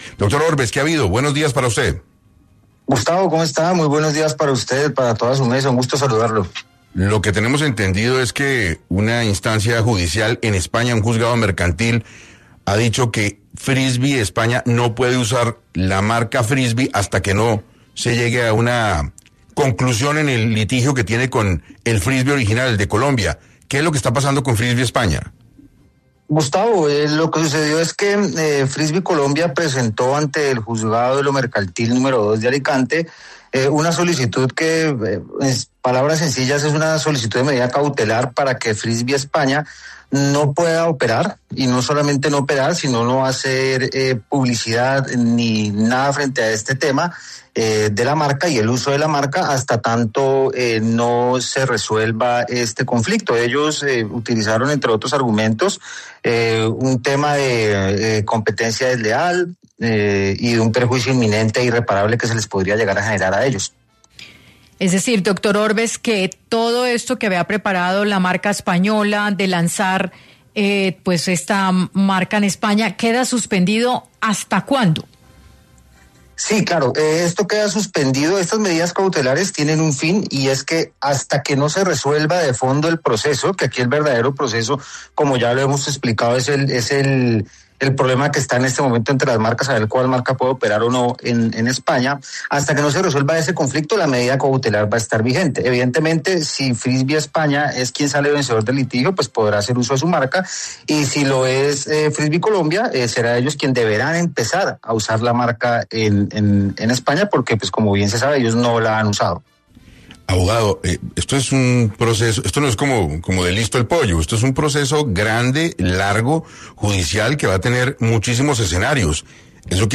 ¿Freno a Frisby España? Juzgado ordena bajar publicaciones y su página web: Abogado explica